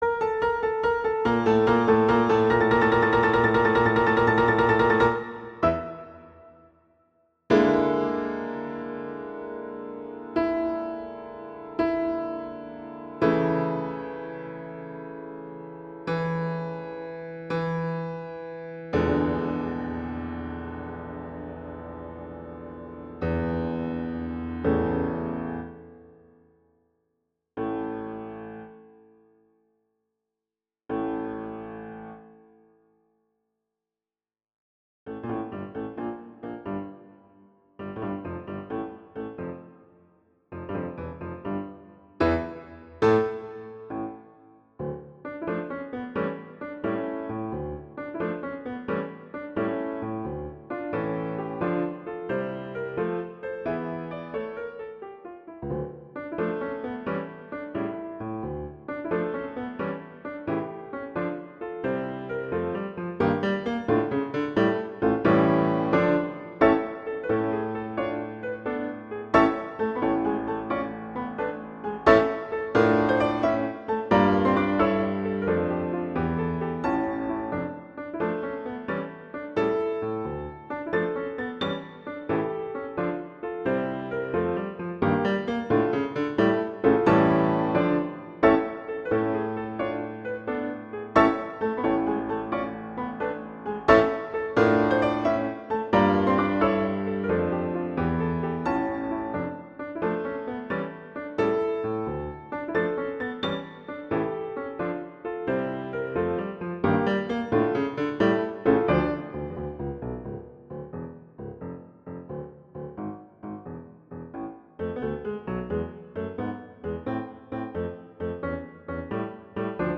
classical, film/tv, halloween, french, movies
F major
♩. = 96 BPM